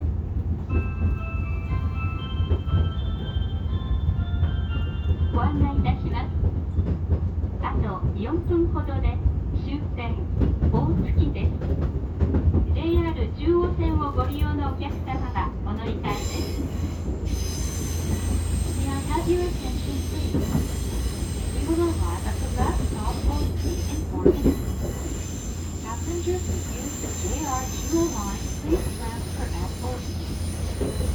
・8000系車内放送
大月駅到着時の放送は2回流れ、1回目は到着4分前に流れます。車内チャイムは高速バスなどで良く聞くことが出来る（レゾナント製？）チャイムを用いていますが、放送の文面が長い場合は途中から放送が被るようです。その放送の声もバスで良く聞く声を用いているので、あまり特急列車らしくはありません。